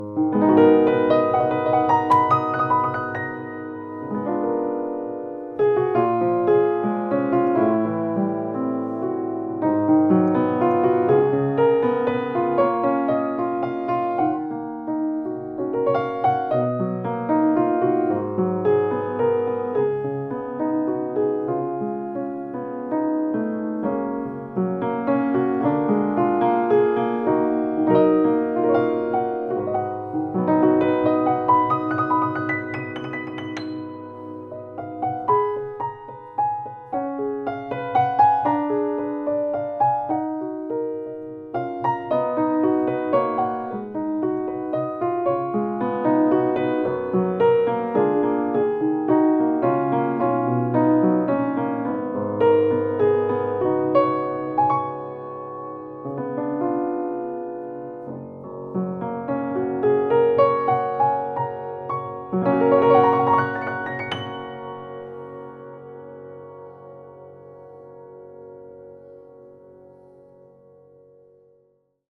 De Wilh. Steinberg IQ16 is een prachtige gebruikte piano in Duitsland gebouwd en door onze werkplaats natuurlijk perfect afgewerkt tot een prachtig spelend en klinkend instrument met een prachtig Duits geluid.
Wilh. Steinberg Piano